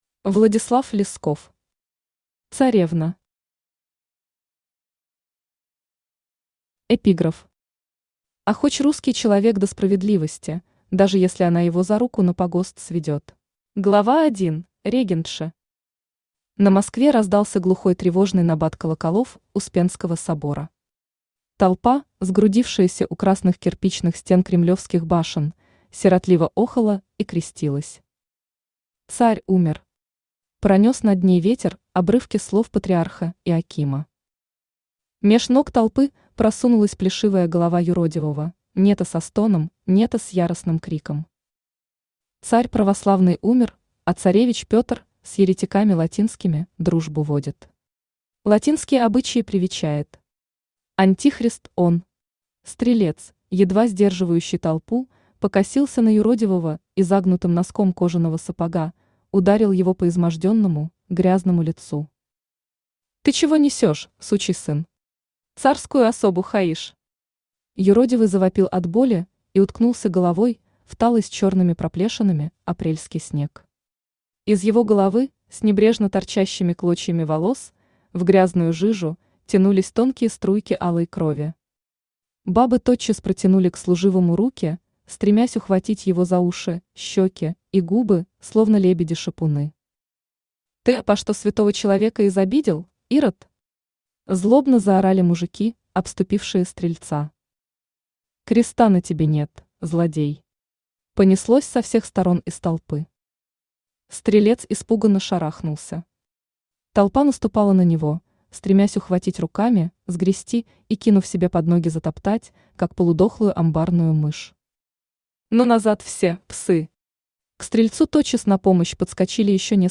Аудиокнига Царевна | Библиотека аудиокниг
Aудиокнига Царевна Автор Владислав Лесков Читает аудиокнигу Авточтец ЛитРес. Прослушать и бесплатно скачать фрагмент аудиокниги